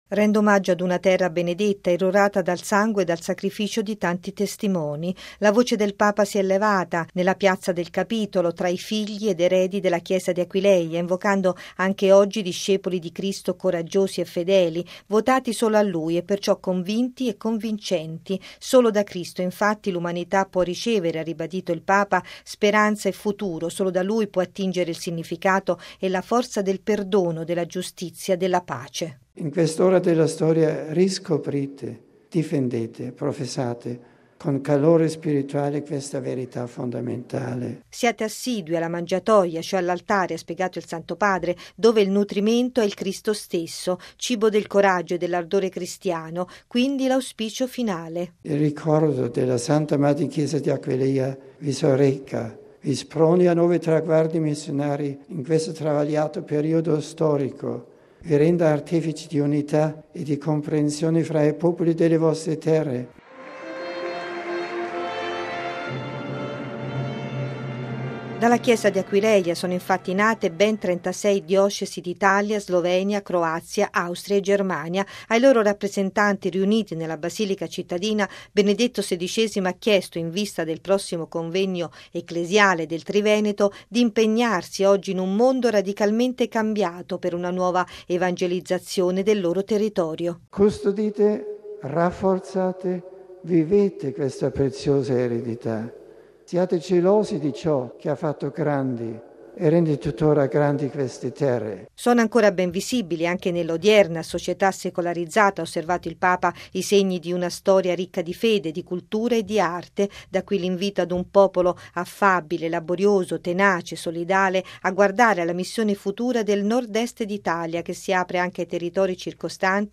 La voce del Papa si è levata nel piazza del Capitolo tra i figli ed eredi della Chiesa di Aquileia, invocando anche oggi “discepoli di Cristo coraggiosi e fedeli, votati solo a Lui e perciò convinti e convincenti”.